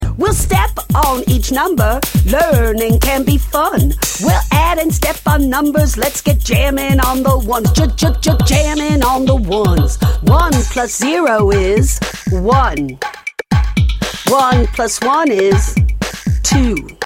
Addition Rap Lyrics and Sound Clip